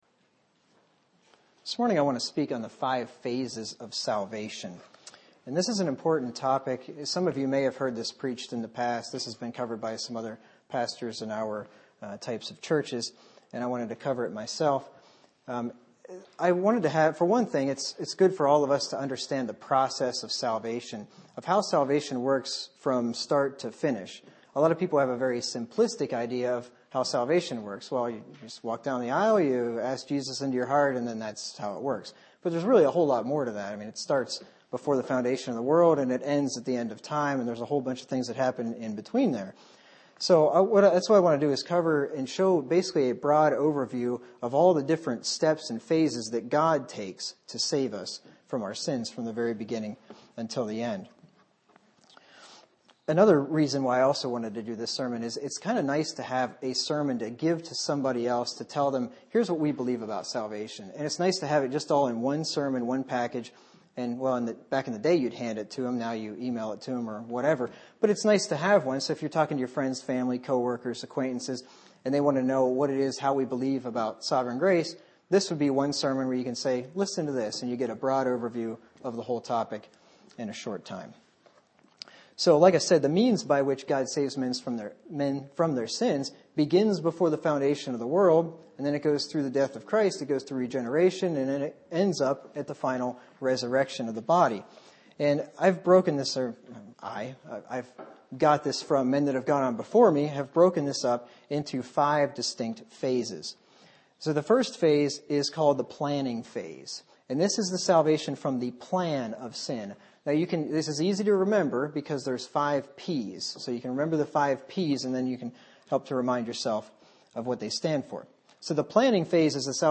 Format: MP3 Mono